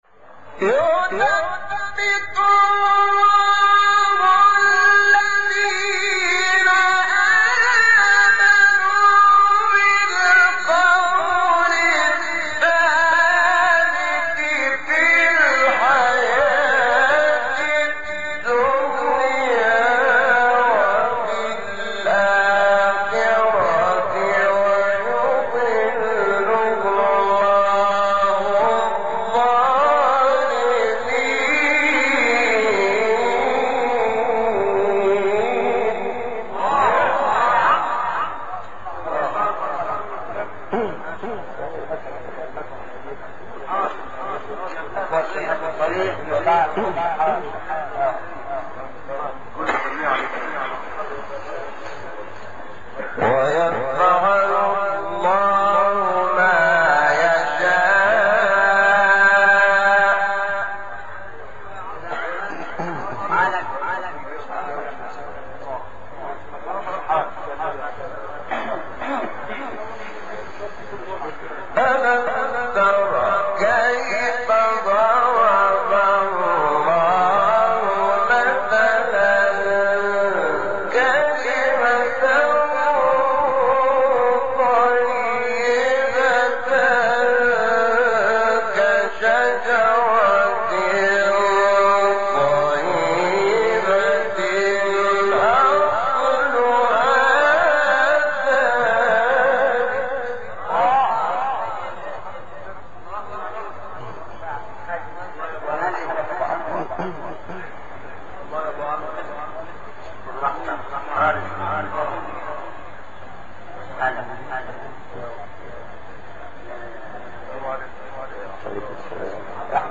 سوره : ابراهیم آیه: 24-27 استاد : شحات محمد انور مقام : رست قبلی بعدی